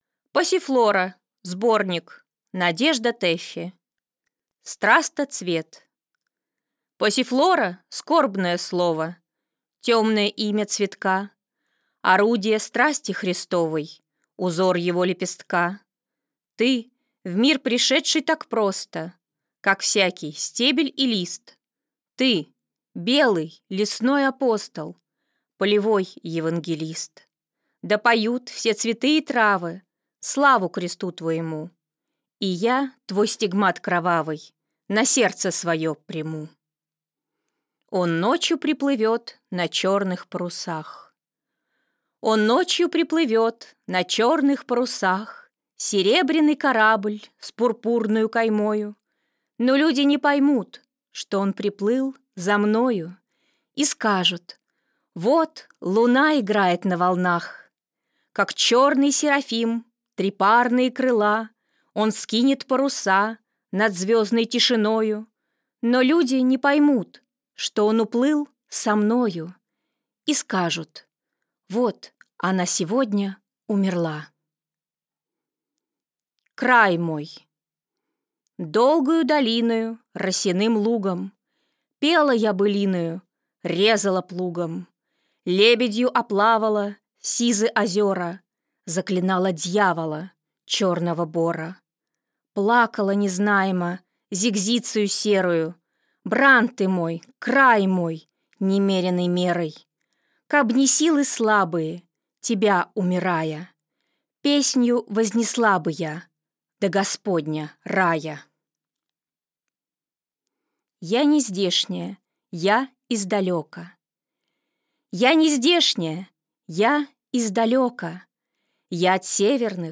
Аудиокнига Passiflora (сборник) | Библиотека аудиокниг